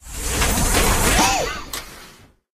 scrap_outof_machine_01.ogg